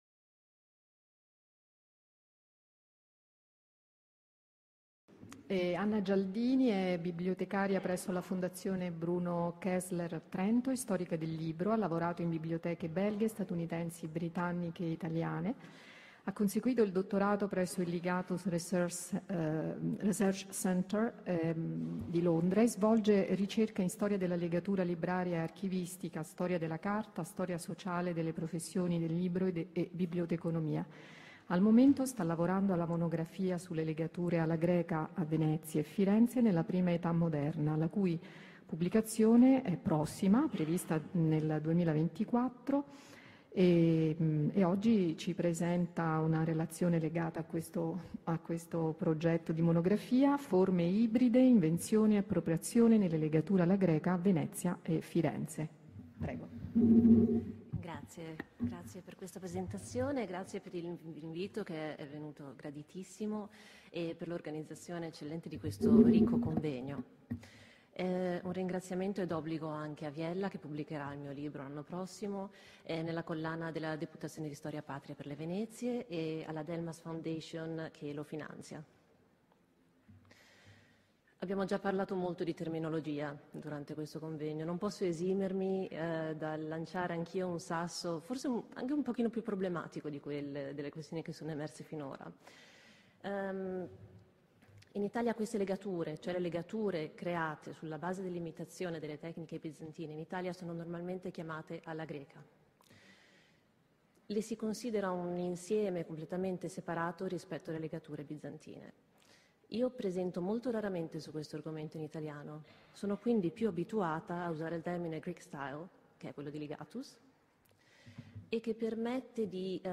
Materiali CONVEGNO INTERNAZIONALE LA LEGATURA DEI LIBRI ANTICHI – AICRAB
Il Convegno Internazionale è stato organizzato dall’Associazione Italiana dei Conservatori e Restauratori degli Archivi e delle Biblioteche con la Biblioteca Malatestiana e il Comune di Cesena.